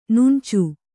♪ nuṇcu